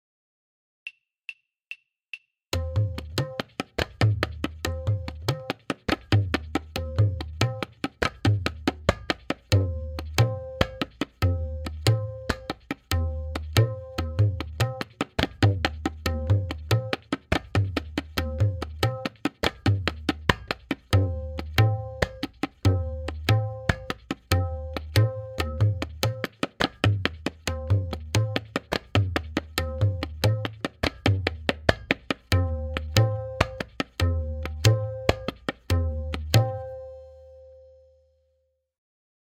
Note: The following forms are all played in dugun (2:1).
Sadharan Chakradar
M8.5-Sadharan-Click.mp3